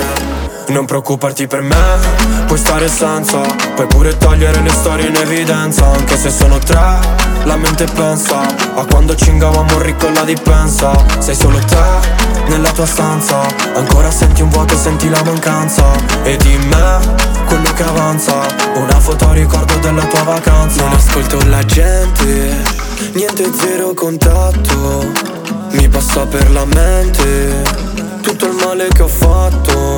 Жанр: Африканская музыка
# Afro-Beat